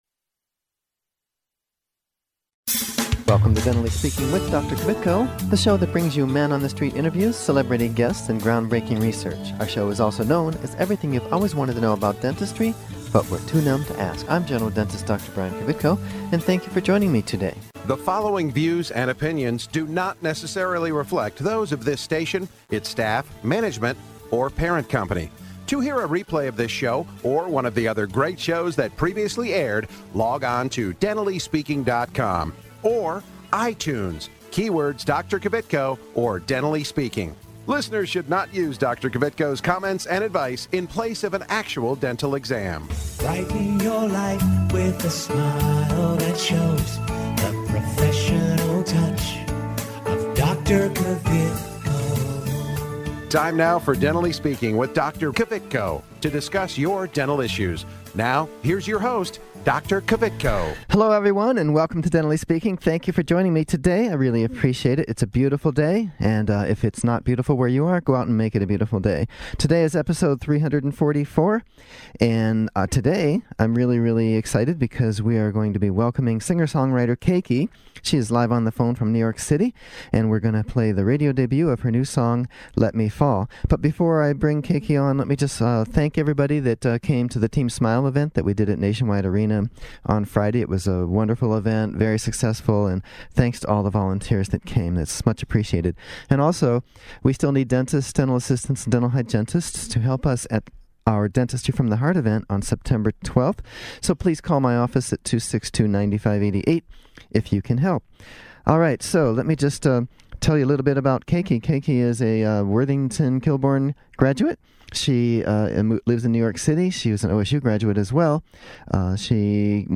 A blend of pop, alternative, and baroque arrangements create a sound that has earned the young artist a considerable buzz.